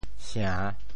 圣（聖） 部首拼音 部首 土 总笔划 5 部外笔划 2 普通话 shèng 潮州发音 潮州 sêng3 文 sian3 白 潮阳 sêng3 文 sian3 白 澄海 sêng3 文 sian3 白 揭阳 sêng3 文 sian3 白 饶平 sêng3 文 sian3 白 汕头 sêng3 文 sian3 白 中文解释 圣 <形> (形声。
sia~3.mp3